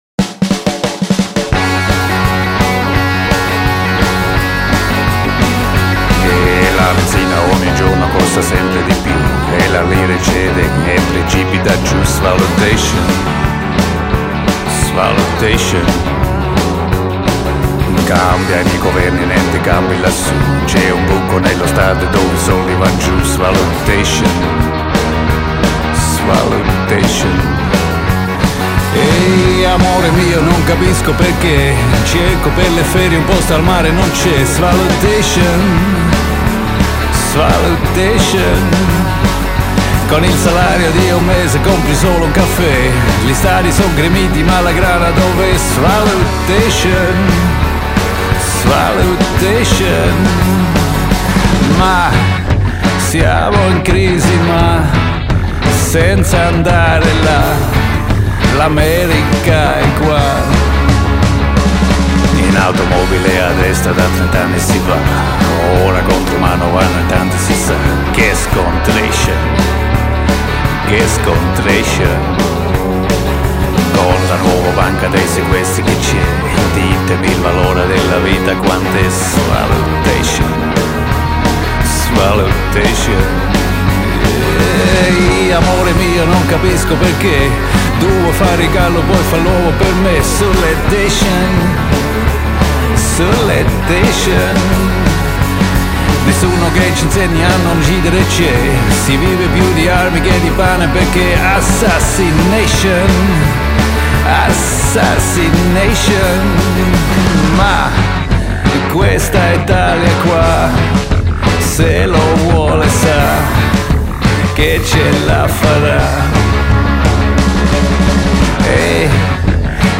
Rock, blues, country.